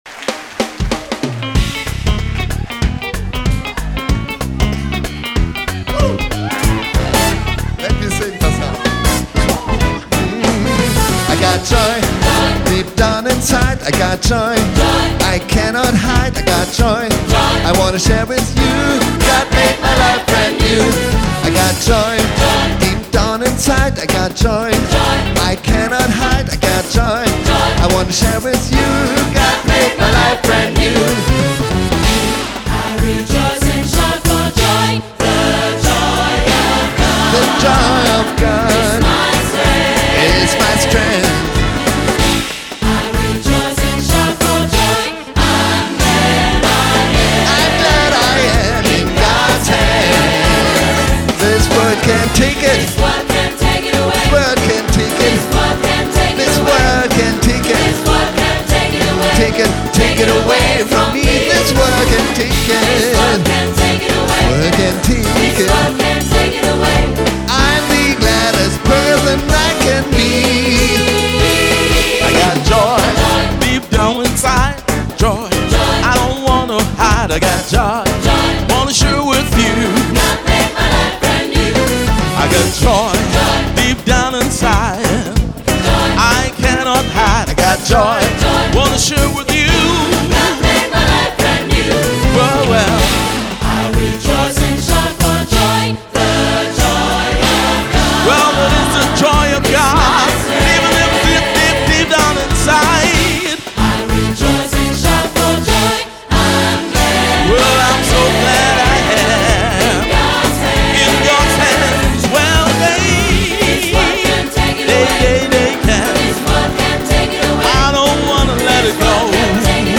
• SAB, auch SSA, Solo + Piano